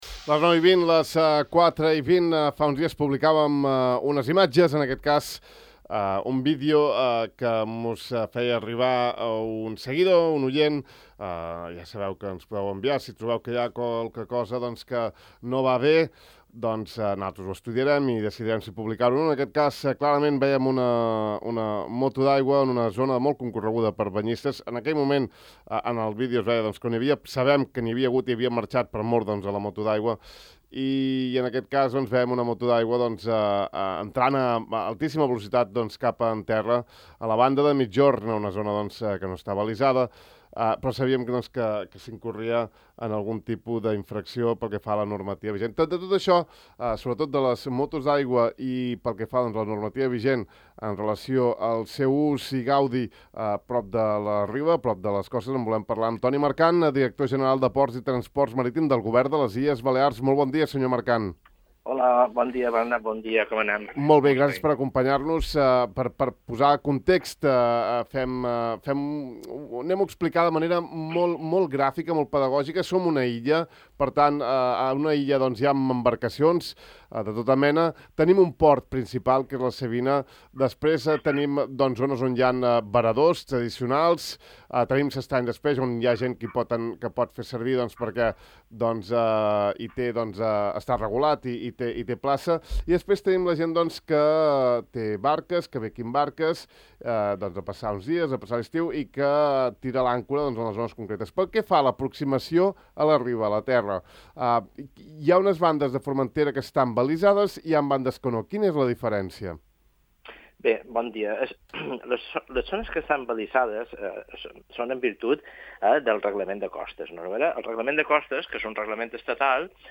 Arran d’unes imatges publicades de poc a Ràdio Illa d’una moto d’aigua accelerant a gran velocitat prop de la riba en zona de banyistes, hem entrevistat al director general de Ports i Transport Marítim del govern de les Illes Balears, Toni Mercant, perquè ens detallés bé la normativa de navegació vora la riba, en zones abalisades i no abalisades.